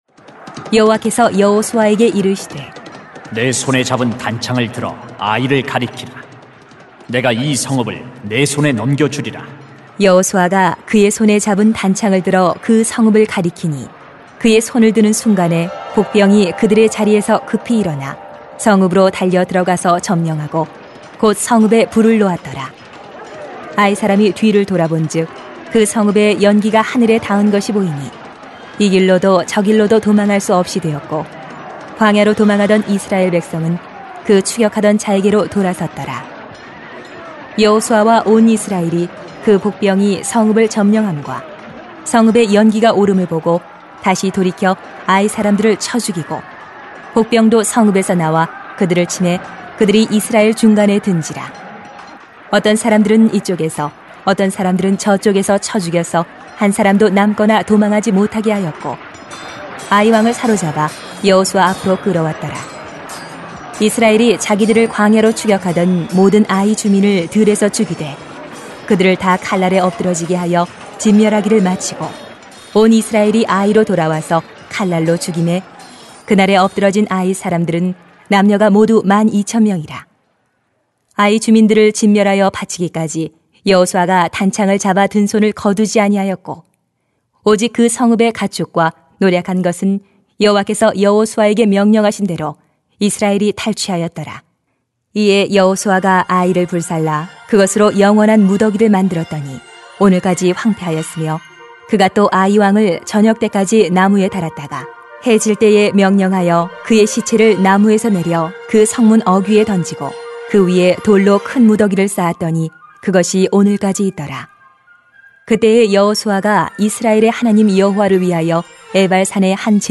[수 8:18-35] 말씀에 매여야 승리합니다 > 주일 예배 | 전주제자교회